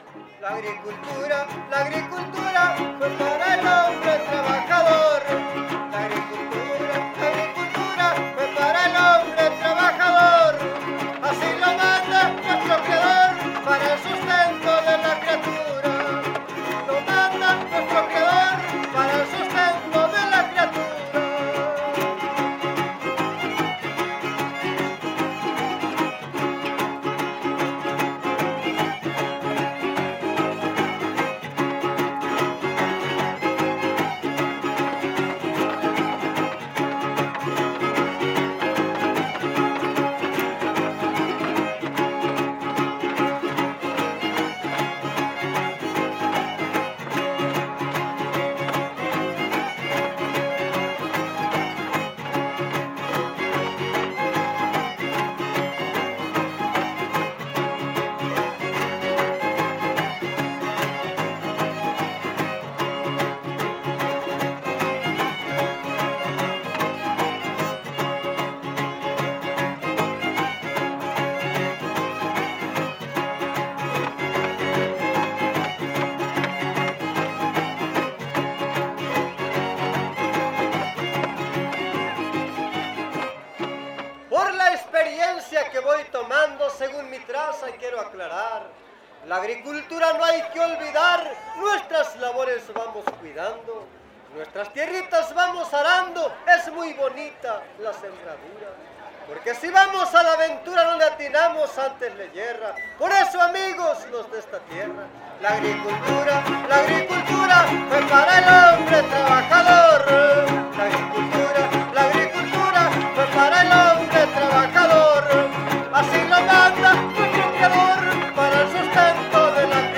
Décima
Huapango arribeño
No identificado (violín segundo)
Vihuela Violín Guitarra
Topada ejidal: Cárdenas, San Luis Potosí